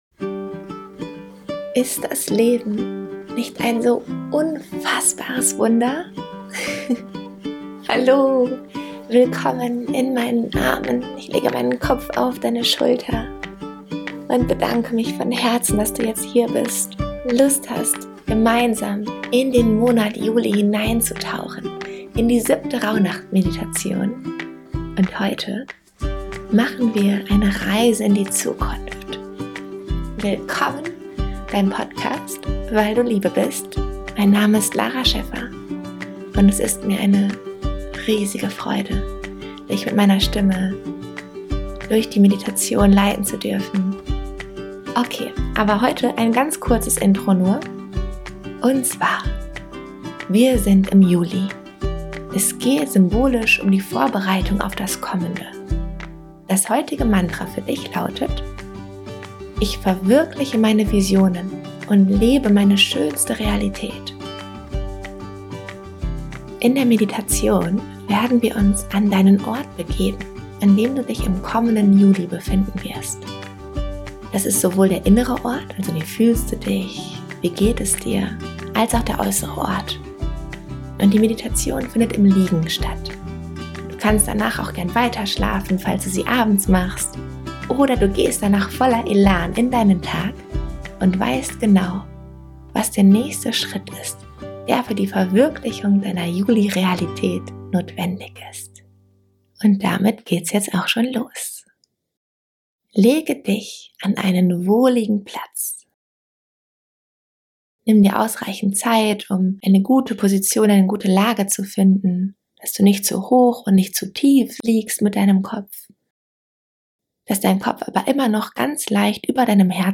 In der heutigen Rauhnächte Meditation (30.-31.12.) befinden wir uns symbolisch im Monat Juli des neuen Jahres. Es geht um die Vorbereitung auf das Kommende.